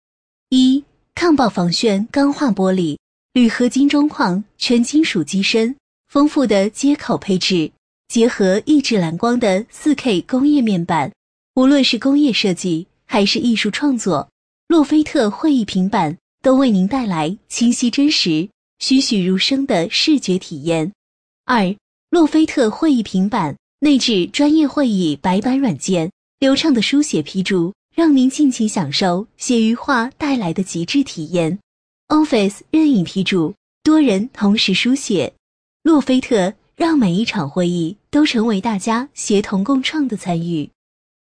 【女50号解说】洛菲特
【女50号解说】洛菲特.mp3